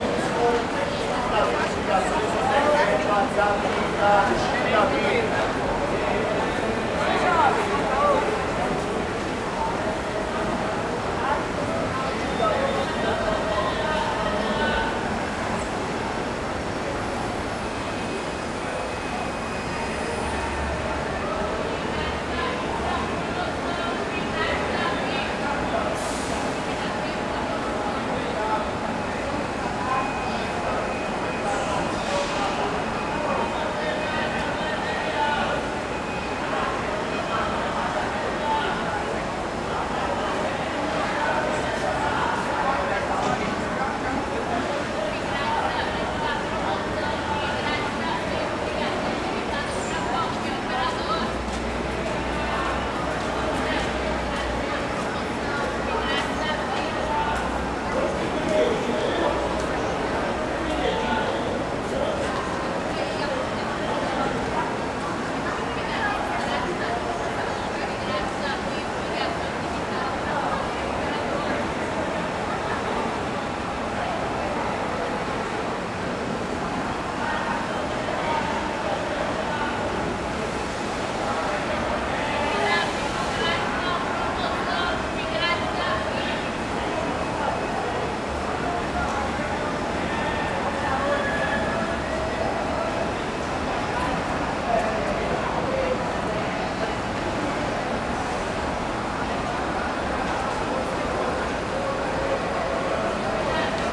CSC-04-188-LE - Ambiencia rodoviaria do plano piloto perto dos ambulantes com aspirador de chao e passos.wav